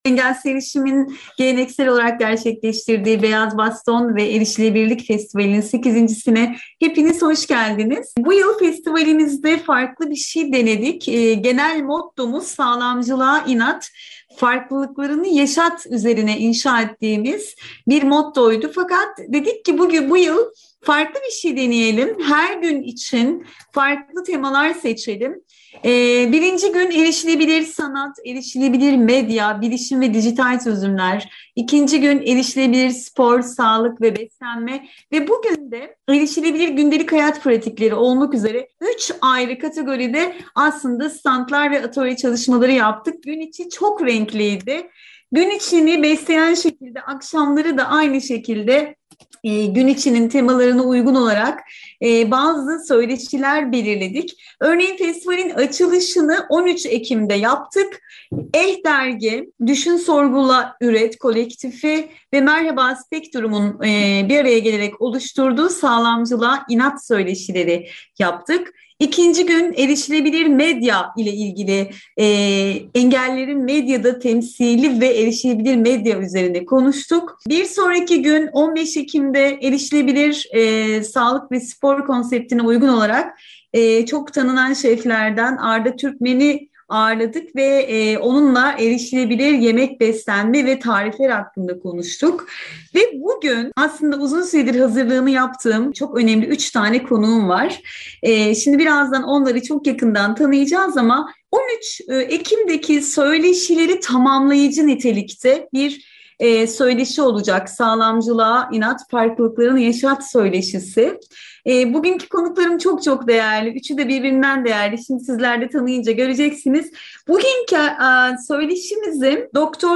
Birbirinden farklı yeti farklarına sahip misafirlerimizle, sağlamcılığın sonuçlarını yaşayan farklı gruplar olarak meselenin ortak kesişim noktalarını tartıştığımız bu zihin açıcı programın konuklarını ve bizlere anlattıklarını kısaca şöyle belirtelim.